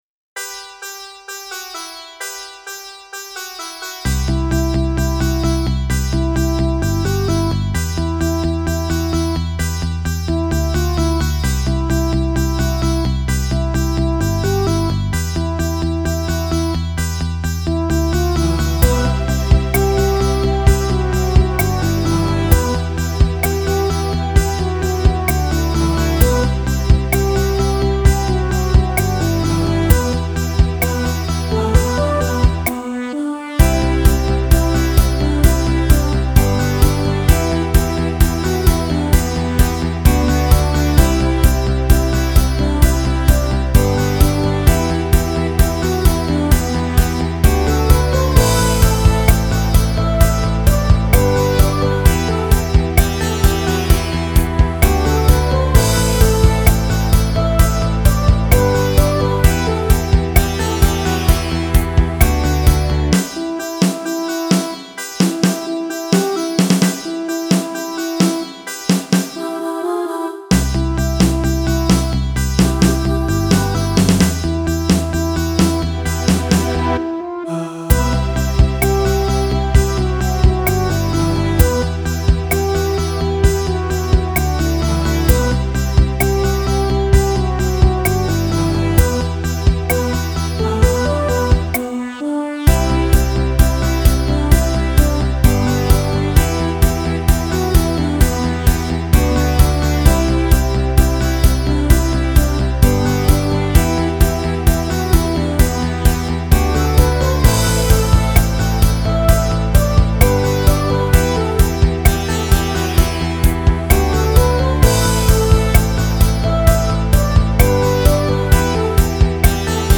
Not to worry, the sitar is still there.